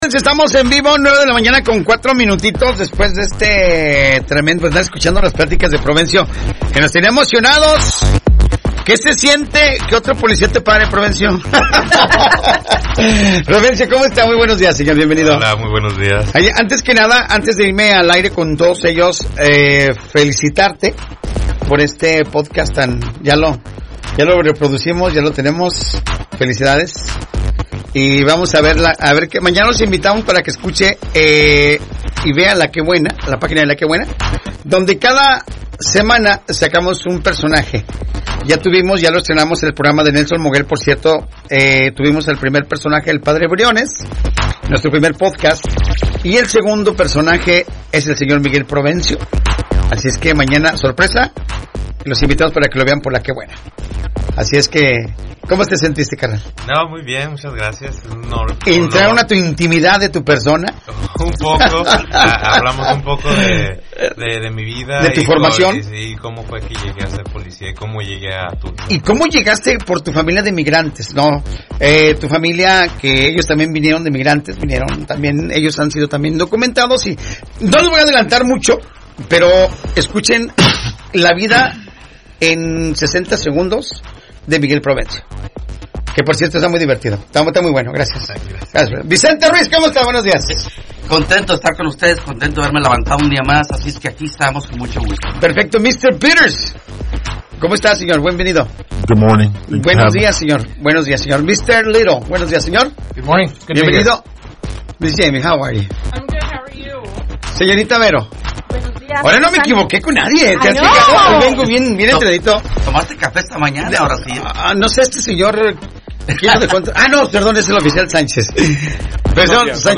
👮‍♂ Invitados de hoy: Policía de Bixby, Policía de Tulsa, Sheriff de Tulsa, Fiscalía del Condado de Tulsa, Bomberos de Tulsa y el Concejal Municipal del Distrito 6 de Tulsa.
En este nuevo episodio, los oficiales compartieron consejos de gran importancia y atendieron llamadas de la comunidad con diversas dudas. 📞